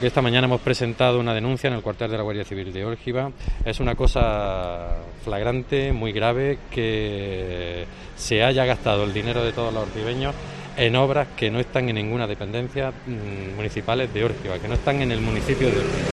Raúl Orellana alcalde de Órgiva habla sobre la denuncia